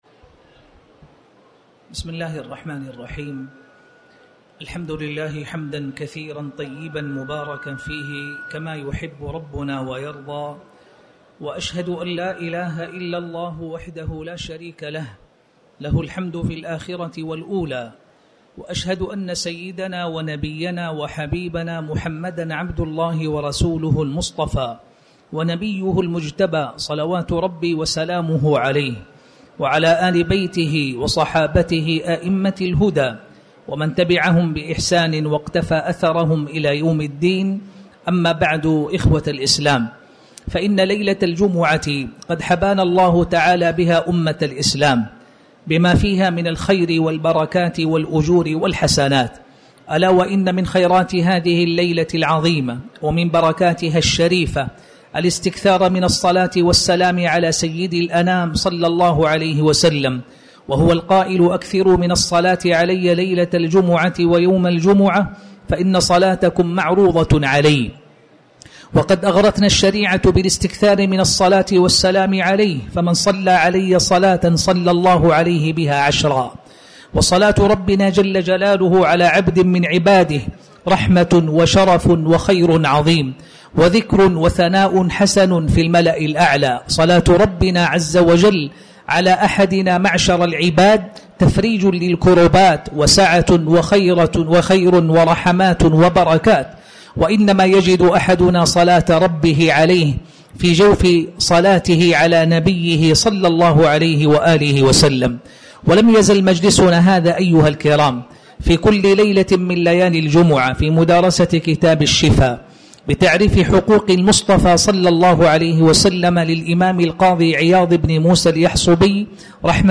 تاريخ النشر ٢٨ ربيع الأول ١٤٤٠ هـ المكان: المسجد الحرام الشيخ